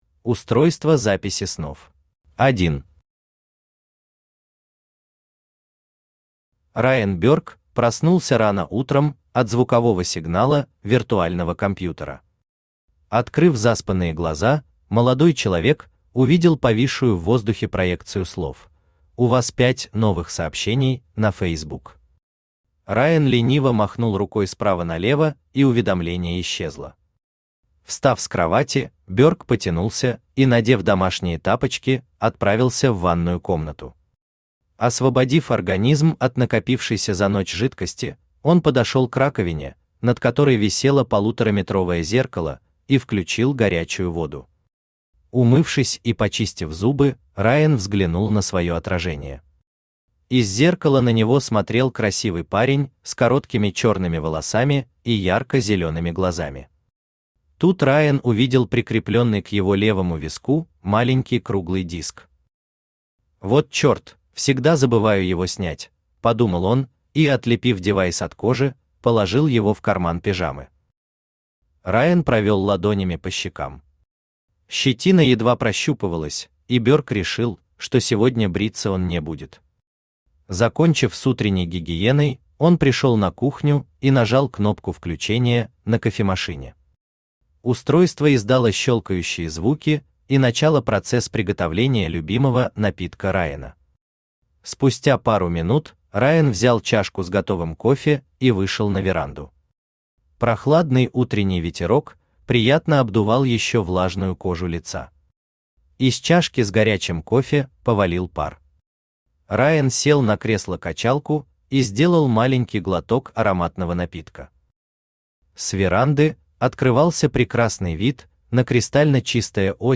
Аудиокнига Сборник фантастических рассказов | Библиотека аудиокниг
Aудиокнига Сборник фантастических рассказов Автор Виктор Геннадьевич Бурмистров Читает аудиокнигу Авточтец ЛитРес.